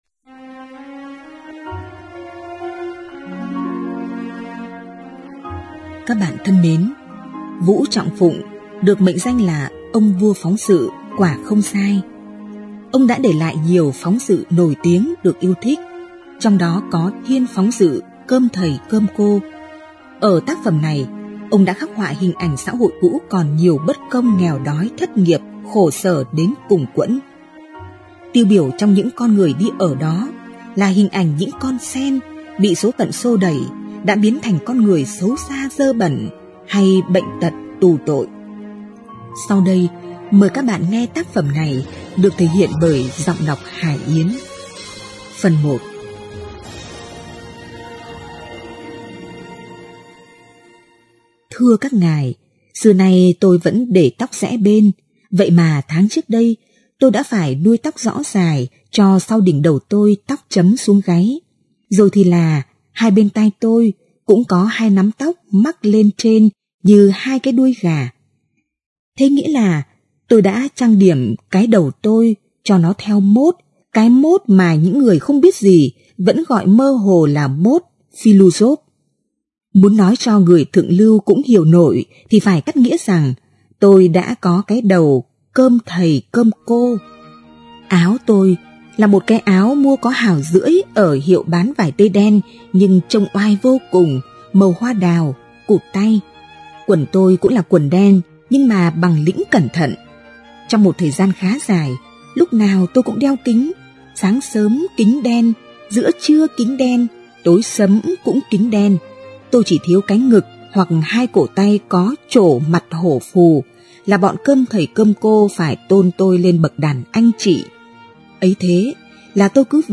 Sách nói | Cơm thầy cơm cô 1